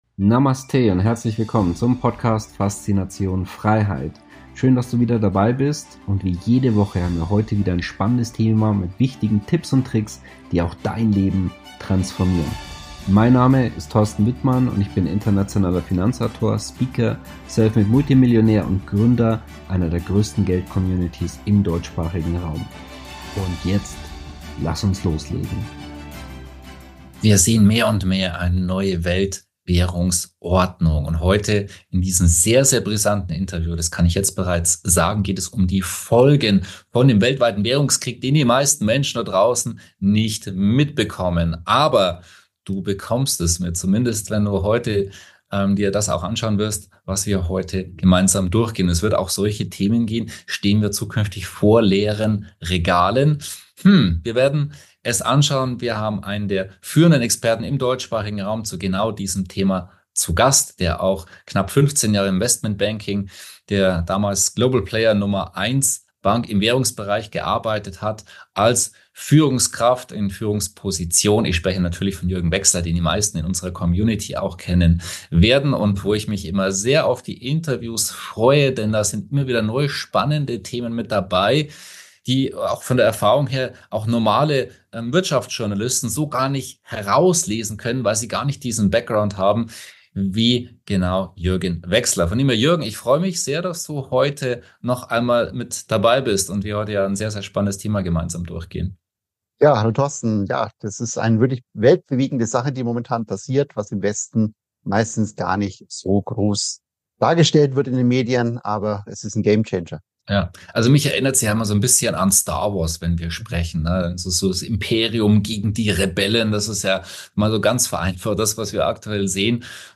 Du erfährst folgende Dinge im heutigen Interview: - Was sind die faszinierenden Einsatzmöglichkeiten der neuen rohstoffgedeckten digitale BRICS-Handelswährung?